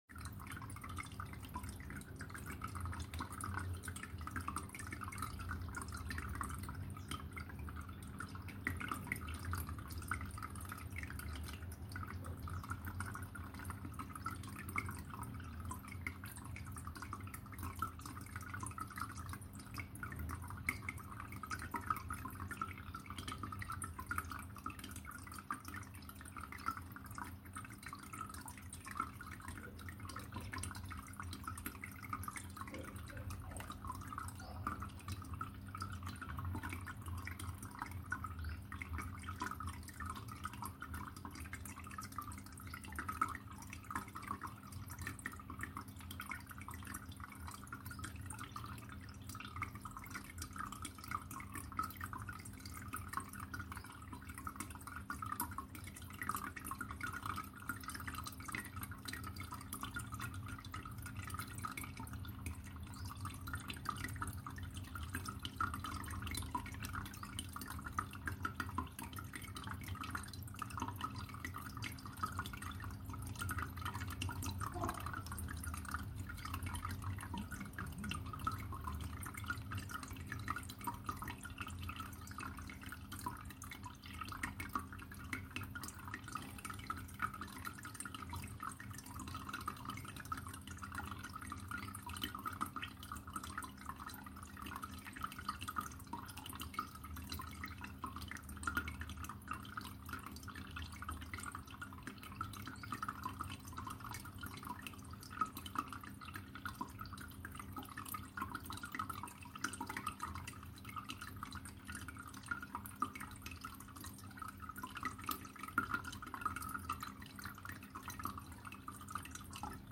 Llave de agua abierta: Efectos de sonido agua cayendo
Categoría: Efectos de Sonido
Este efecto de sonido ha sido grabado para capturar la naturalidad del sonido del agua saliendo de una llave, proporcionando un sonido limpio y claro que se integrará perfectamente en tus proyectos.
Tipo: sound_effect
Llave de agua abierta.mp3